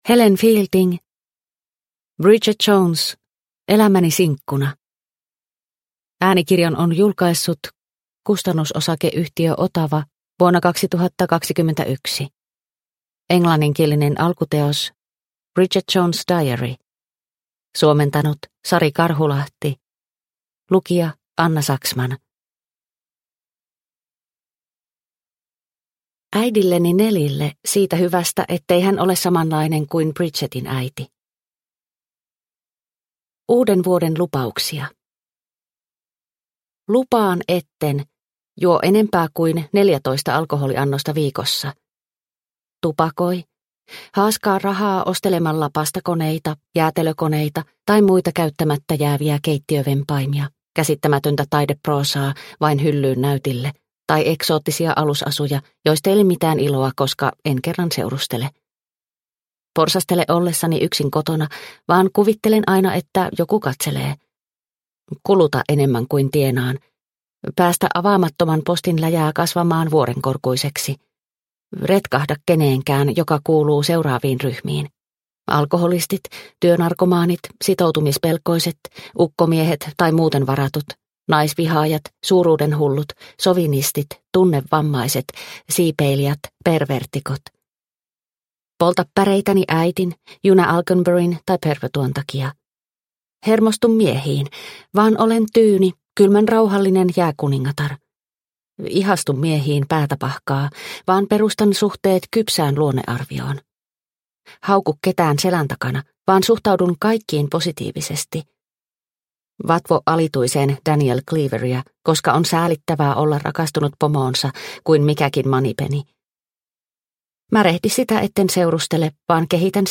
Bridget Jones - elämäni sinkkuna – Ljudbok – Laddas ner